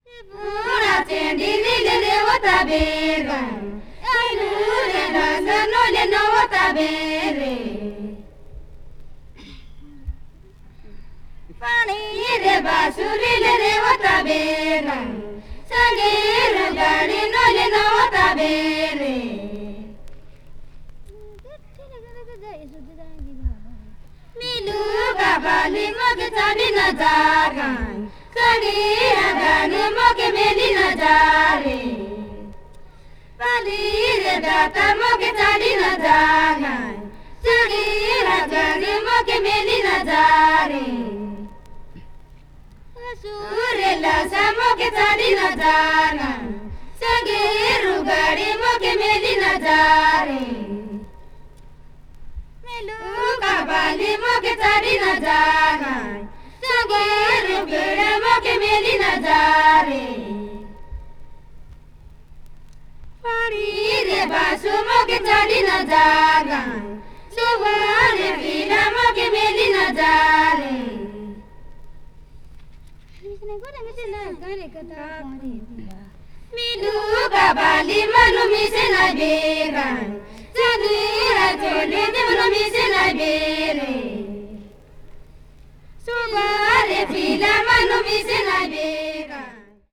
media : EX-/EX-(わずかなチリノイズ/一部軽いチリノイズが入る箇所あり)
ベンガル湾に面するインド東部の州、オリッサの民族音楽の現地録音を収録。
ethnic music   folk   india   oriental   traditional